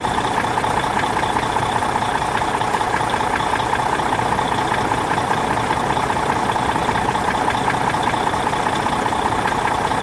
tr_macktruck_idle_lp_01_hpx
Mack truck starts up and idles. Loop. Vehicles, Truck Idle, Truck Engine, Motor